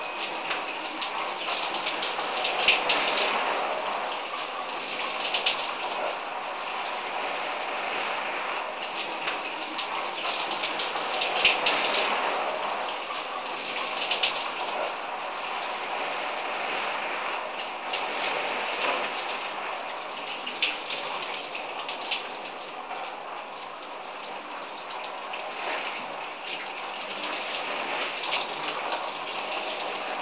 shower.wav